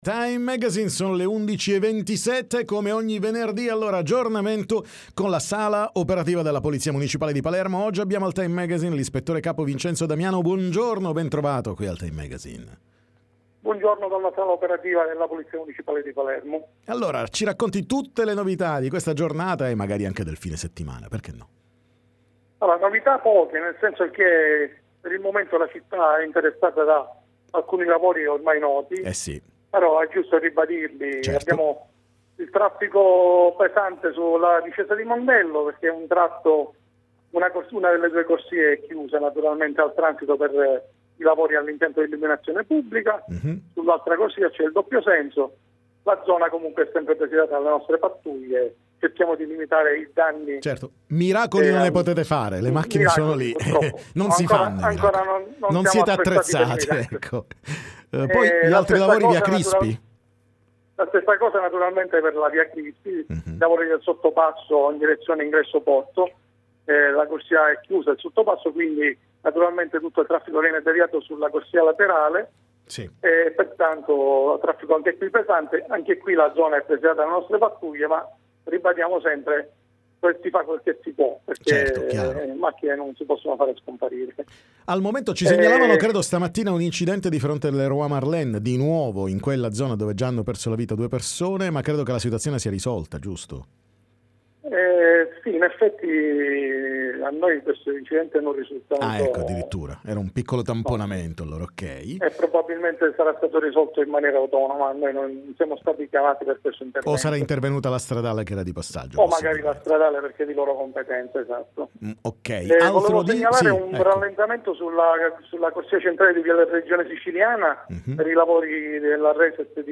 TM Intervista Polizia Municipale